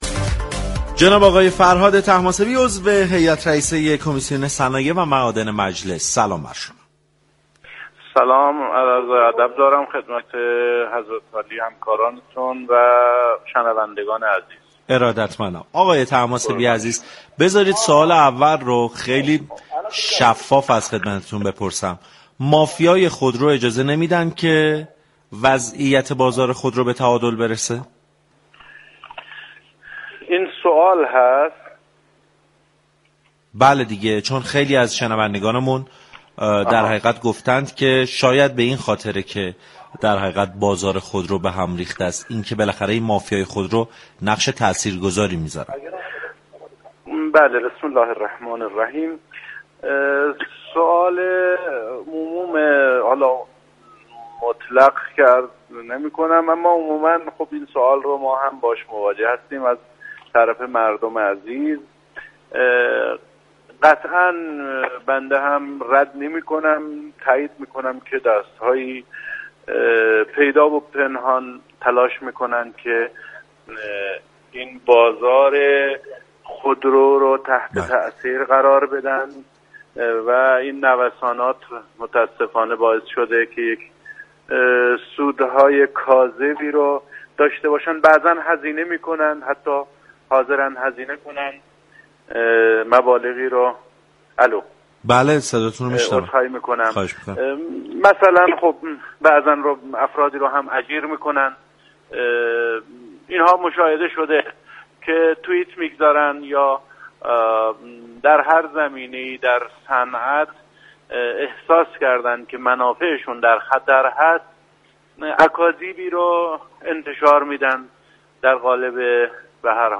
به گزارش پایگاه اطلاع رسانی رادیو تهران، فرهاد طهماسبی عضو هیات رئیسه كمیسیون صنایع و معادن مجلس شورای اسلامی در گفتگو با سعادت‌آباد رادیو تهران در پاسخ به این پرسش كه آیا مافیای خودرو اجازه می‌دهد كه وضعیت بازار خودرو به تعادل برسد یا خیر؟